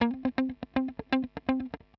120_Guitar_funky_riff_C_4.wav